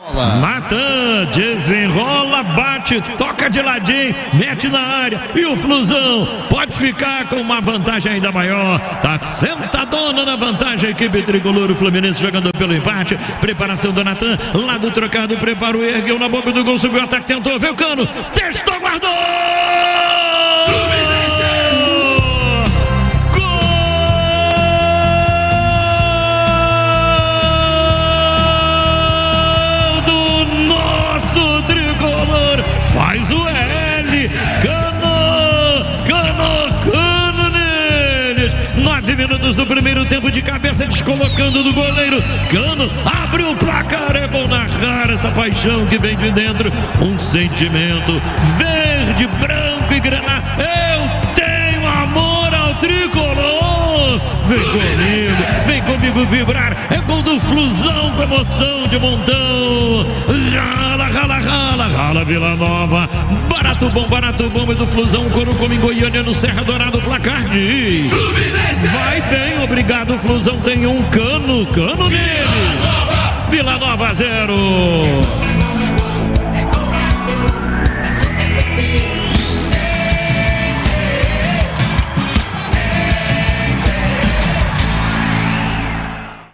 Ouça os gols da vitória do Fluminense sobre o Vila Nova com a narração de Luiz Penido